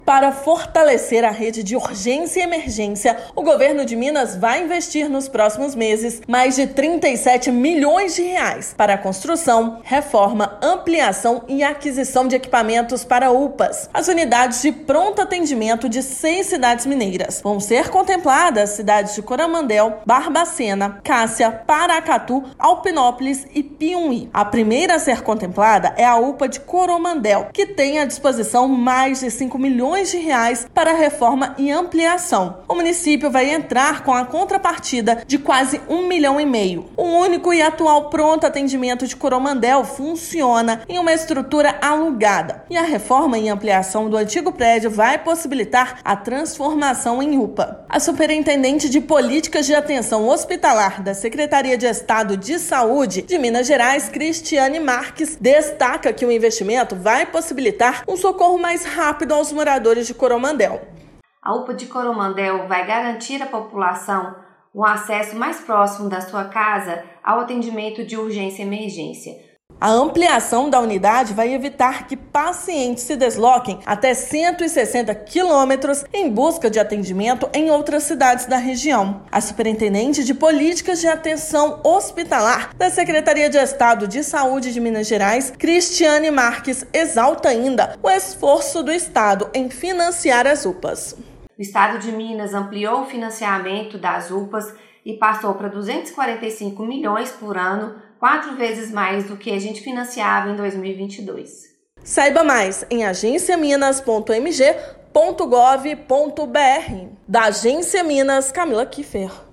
Ampliação da unidade vai evitar que pacientes se desloquem até 160 quilômetros em busca de atendimento em outras cidades da região. Ouça matéria de rádio.